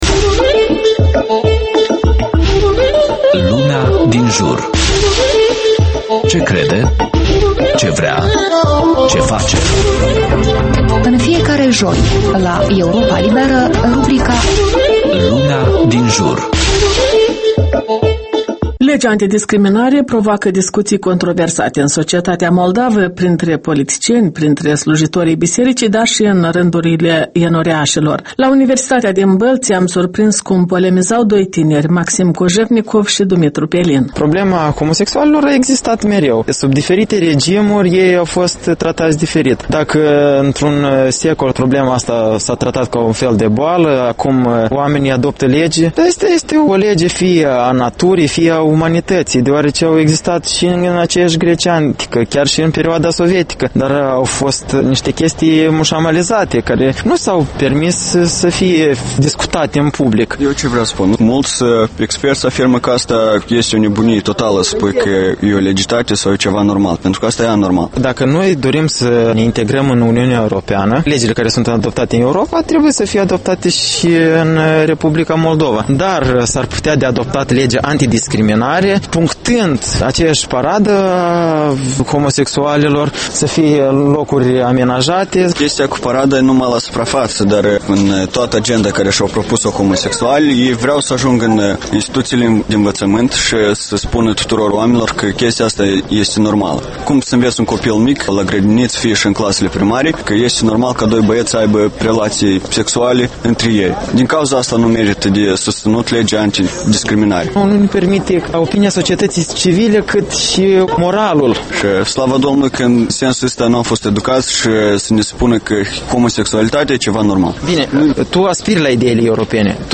Lumea din jur - un reportaj